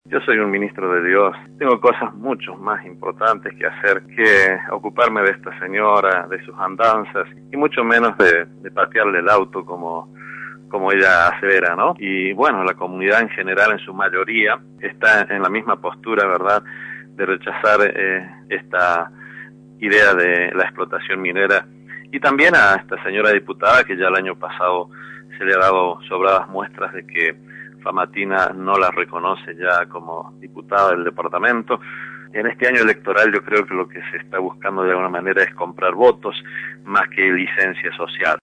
por Radio La Red